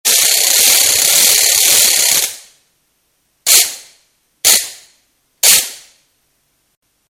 Rattle+attachment
Orchestral Ratchet With Holder